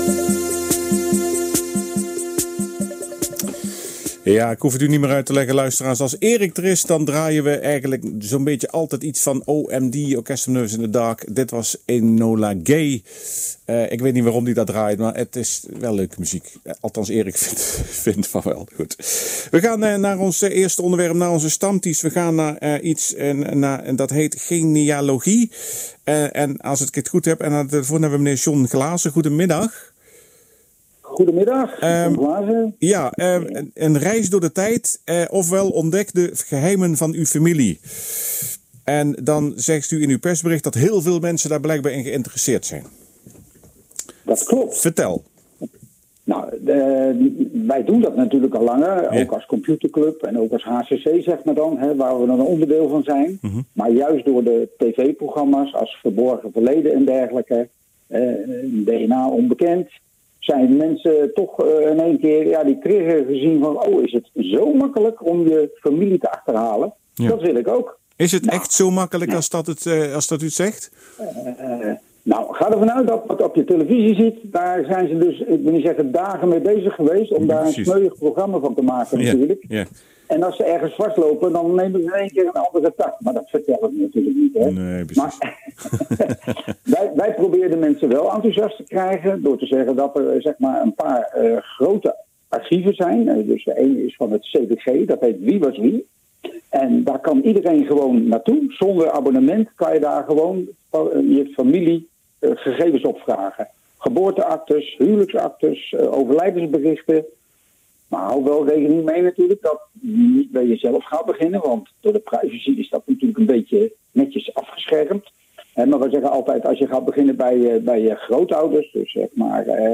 HCC!genealogie - Interview voor Omroep Berg en Dal over de HCC!genealogiedag